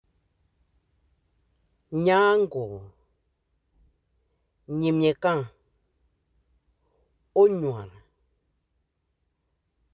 Accueil > Prononciation > ny > ny